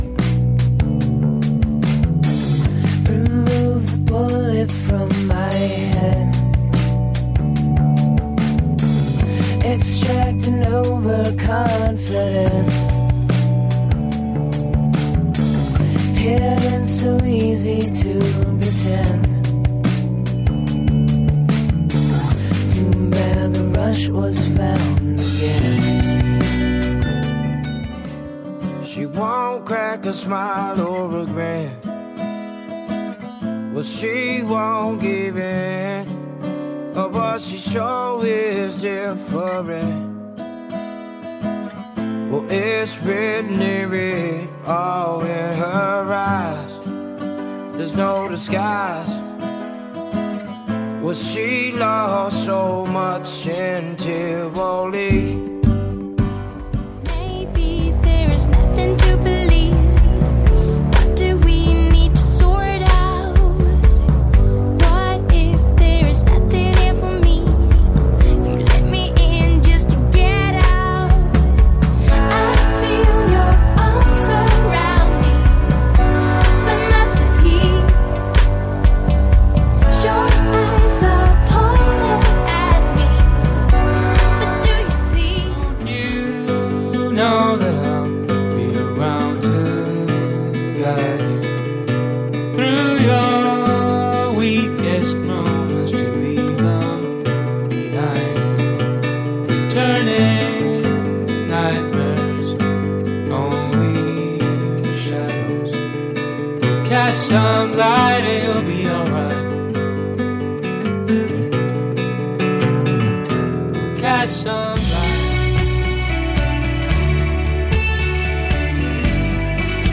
Mellow Alternative Indie
Mellow Alternative Indie Rock Songs